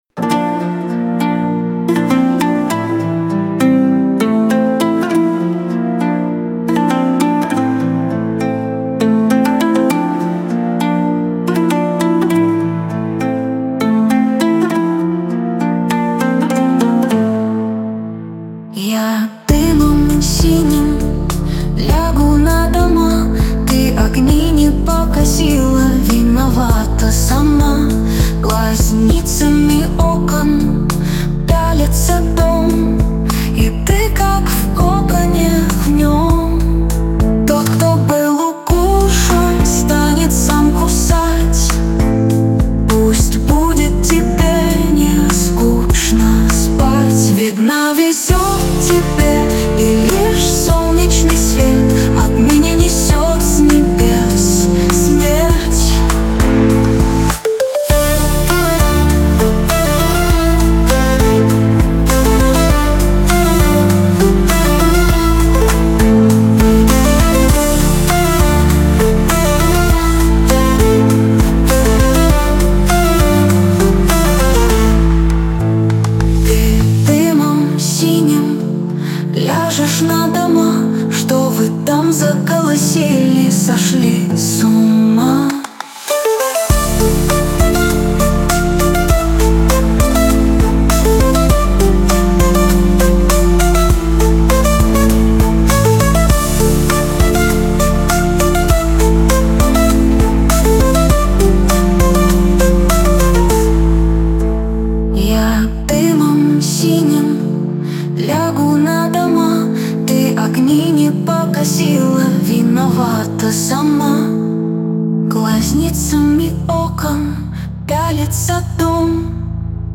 • Жанр: AI Generated
Тут не всё AI. Только голос и часть инструментов.